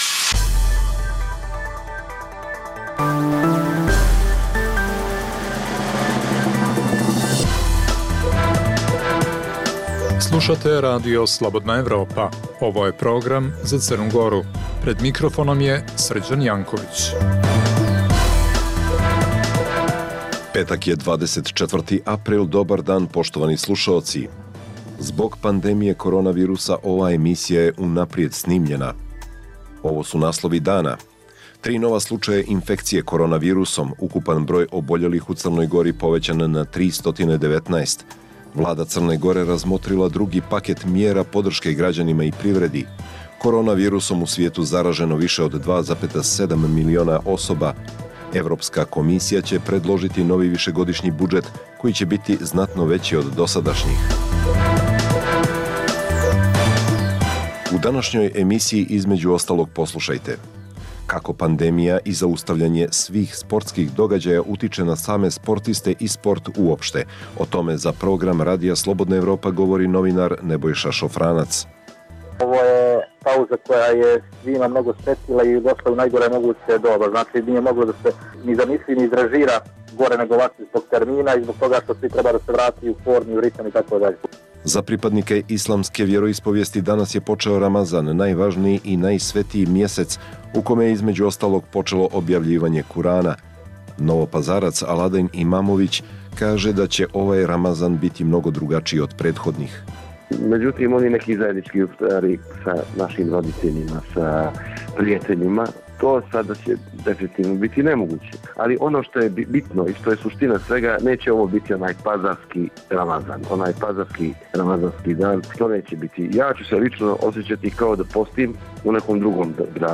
Emisija namijenjena slušaocima u Crnoj Gori. Sadrži lokalne, regionalne i vijesti iz svijeta.